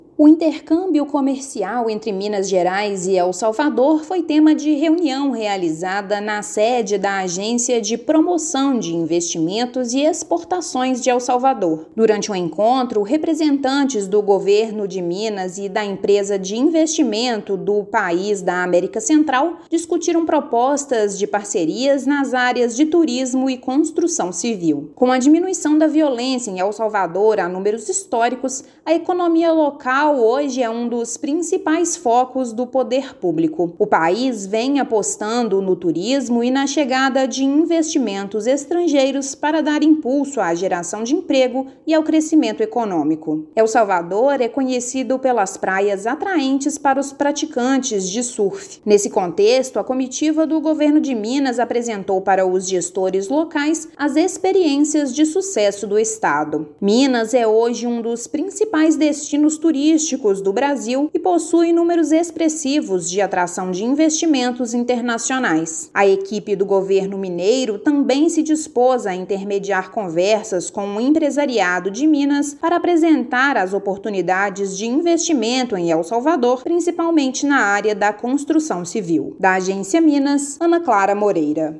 [RÁDIO] Governo de Minas e El Salvador dialogam sobre parcerias nas áreas de infraestrutura e turismo
Com diminuição da violência, país da América Central se abre para parcerias econômicas. Ouça matéria de rádio.